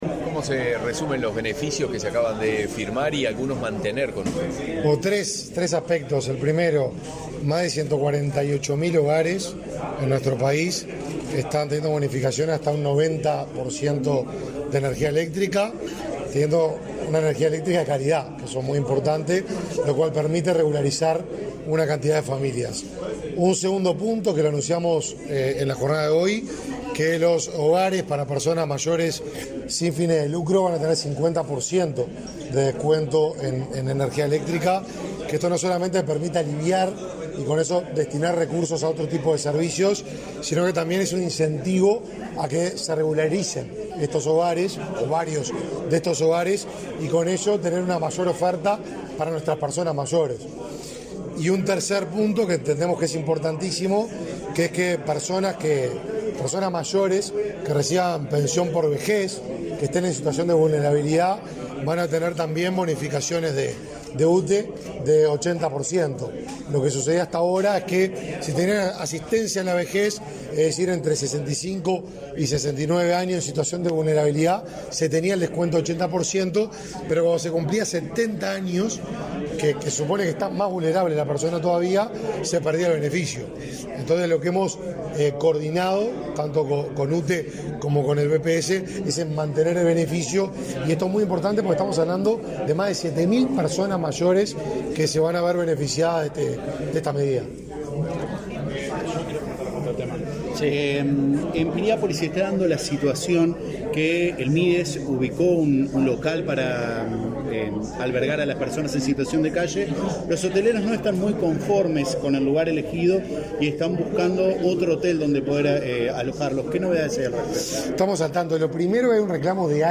Declaraciones a la prensa del ministro del Mides, Martín Lema
Tras participar en el acto de presentación de los nuevos beneficios en las tarifas de consumo de energía eléctrica para hogares de ancianos y usuarios